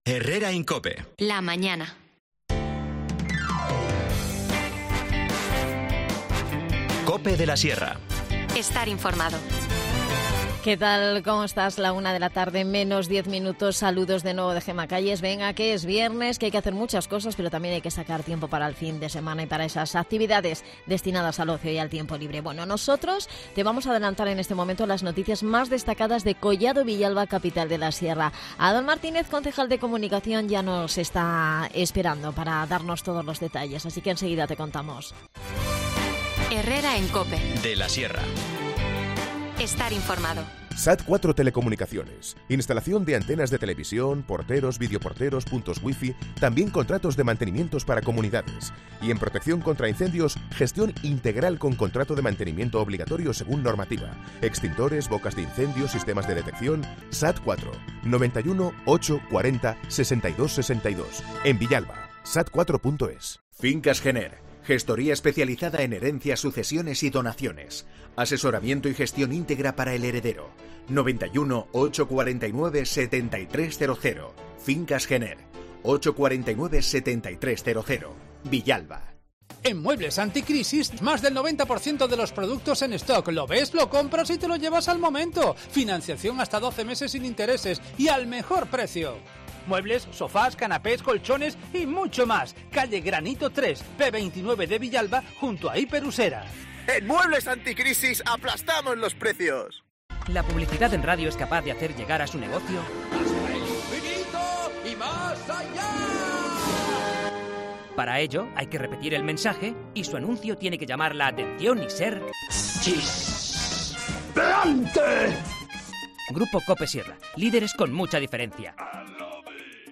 De ello hemos hablado en el espacio Collado Villalba, Capital de la Sierra, con el edil de Comunicación Adan Martínez.